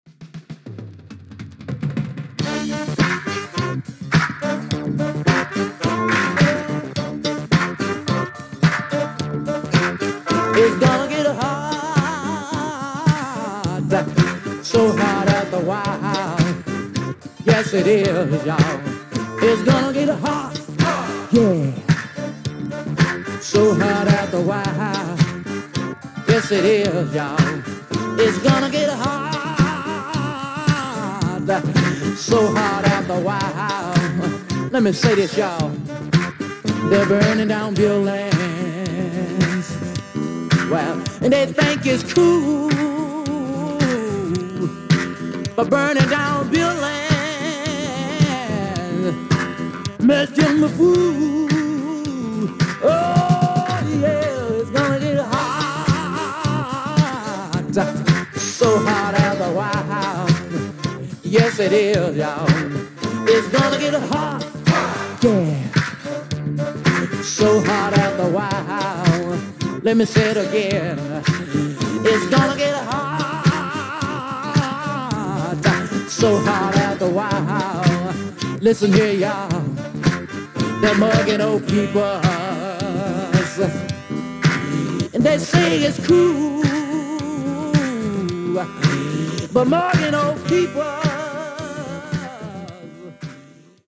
Funk.Discoな感じもあって楽しめると思います。